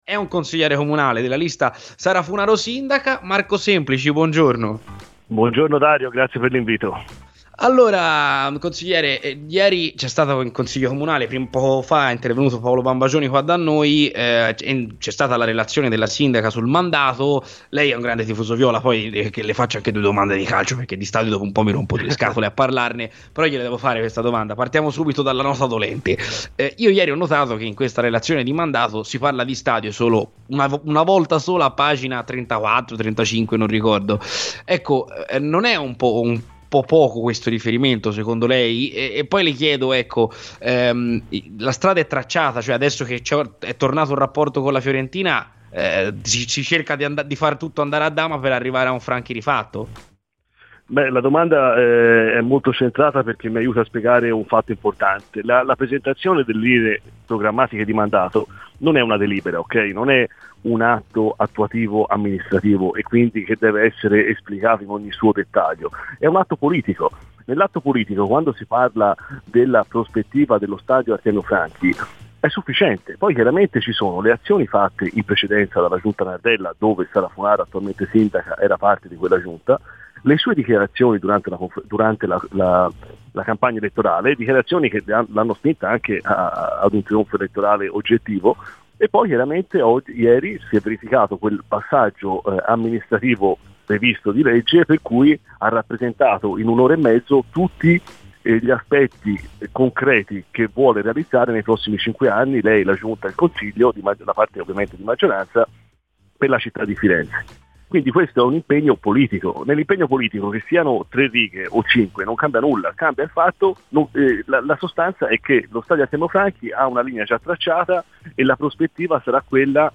Marco Semplici, consigliere comunale di Firenze per la lista Sara Funaro Sindaca, ha parlato nel corso di "C'è Polemica" su Radio FirenzeViola all'indomani della presentazione da parte della stessa prima cittadina del programma di mandato nel corso del quale non si è toccato il tema dello Stadio Artemio Franchi e del suo rifacimento: